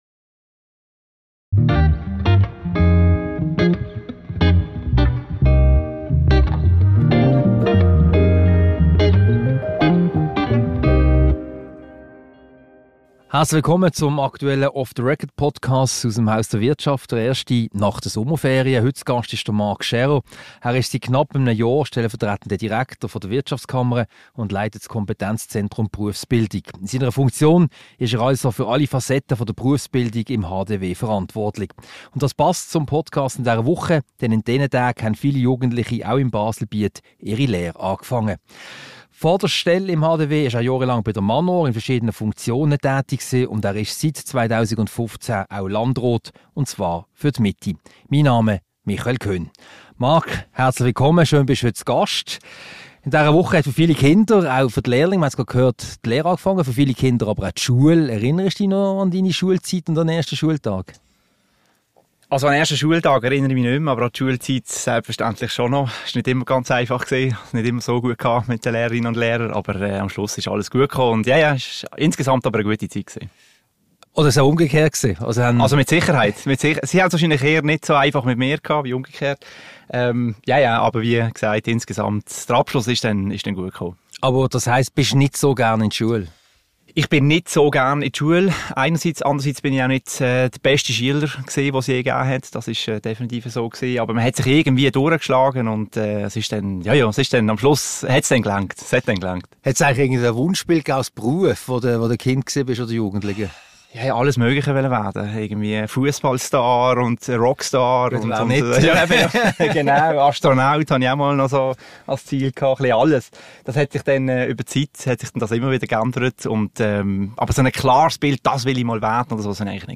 Ein Gespräch über die Ausbildung der Generation Z, die kommende Berufsschau und 11 Mythen zur Berufsbildung.